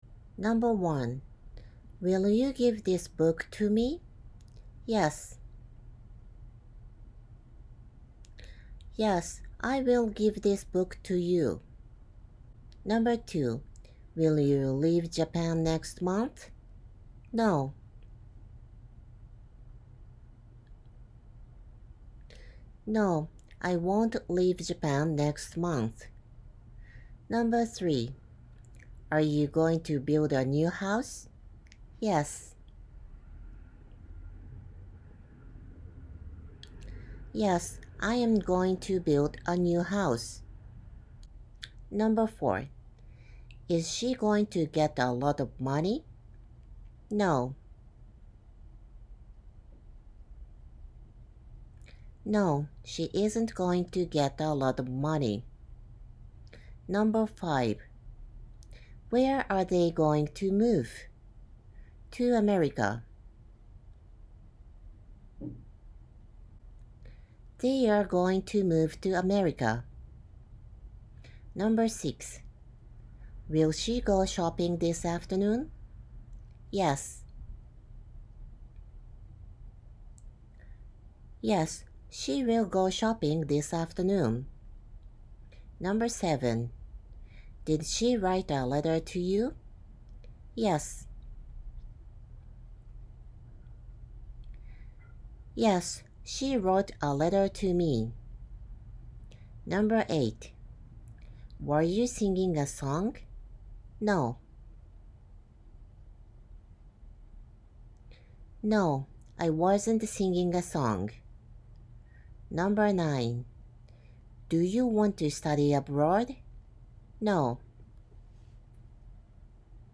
１．私から質問を言います。例１：Are you busy now?例２：When do you watch TV?
２．質問のあとに答えに使用するワードを言います。例1：No例2：after dinner